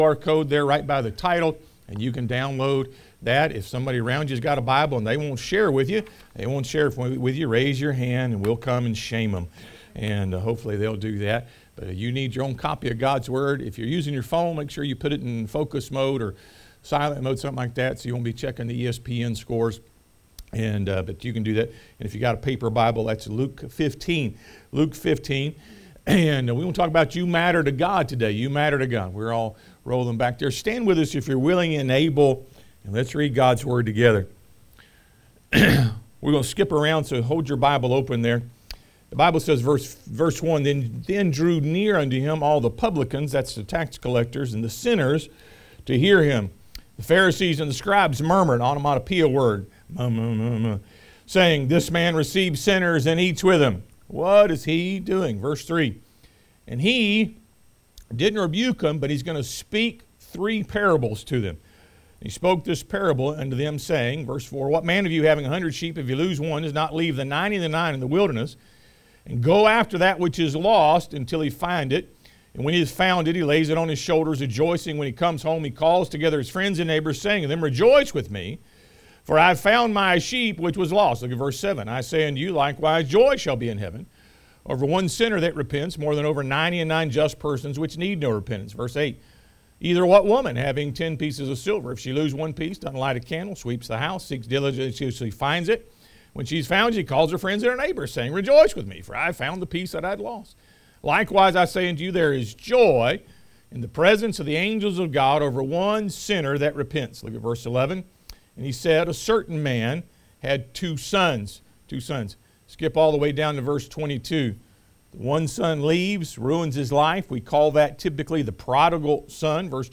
Luke 15 Service Type: Sunday AM You Matter to God Luke 15 3 Parables 4 Lost Things One Savior Who Cares 1. The Lost Sheep The Shepherd searches because he cares about the one sheep.